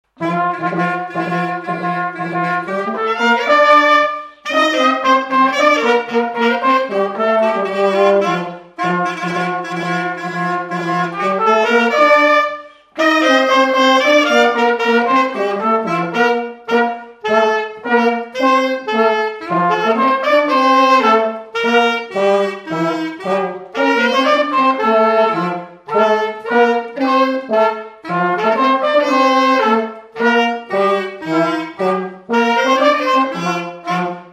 danse : quadrille : galop
Pièce musicale inédite